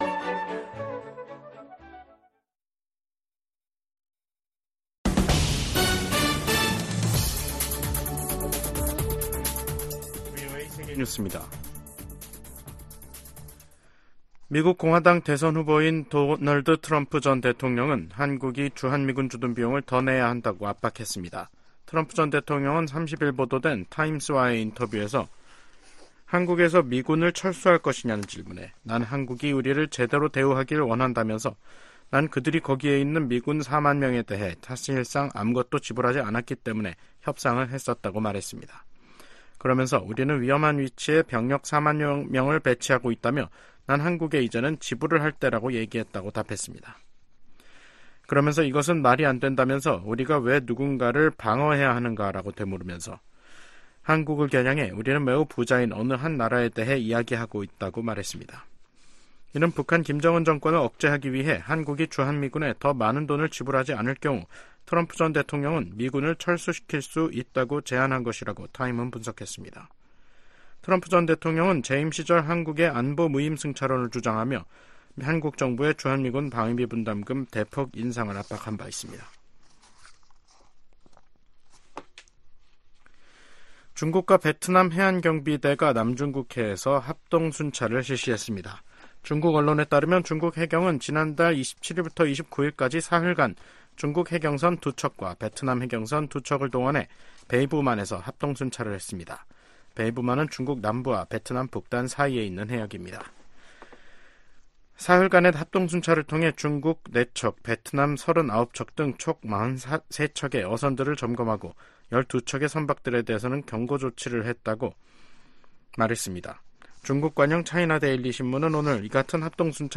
VOA 한국어 간판 뉴스 프로그램 '뉴스 투데이', 2024년 5월 1일 2부 방송입니다.